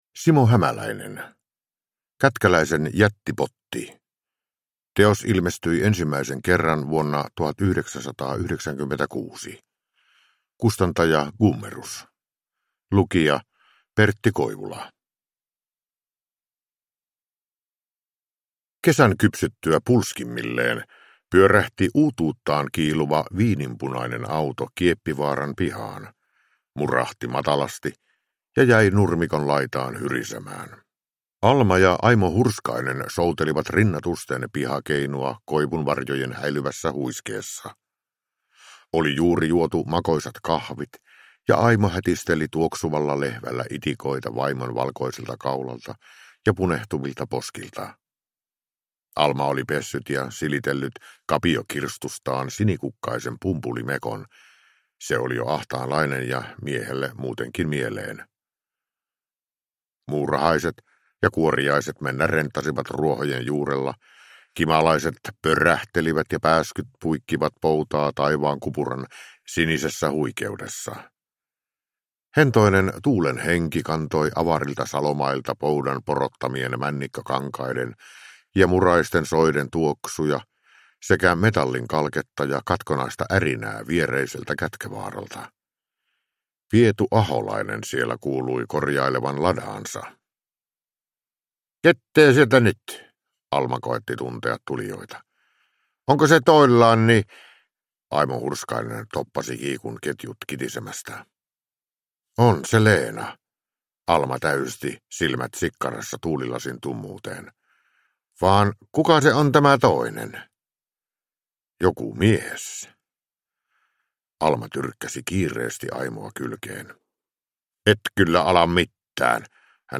Kätkäläisen jättipotti – Ljudbok
Uppläsare: Pertti Koivula